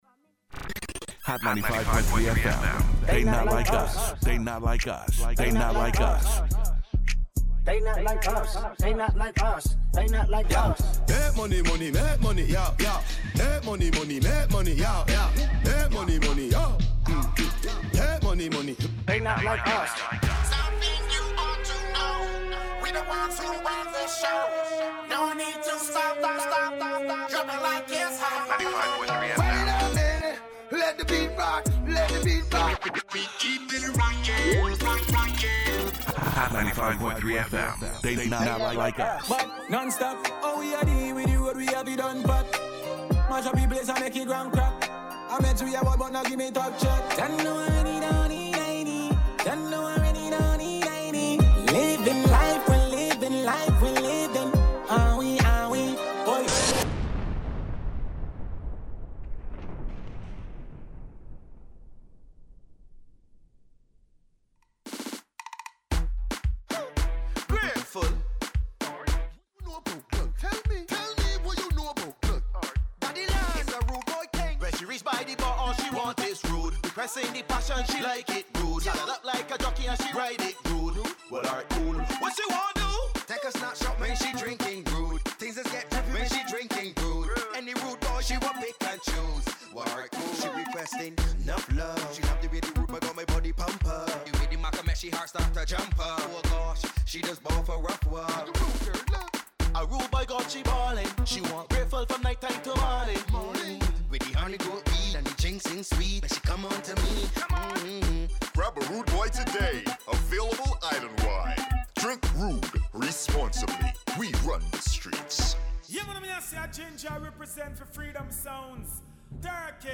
pure vibes, real energy, and non-stop party mode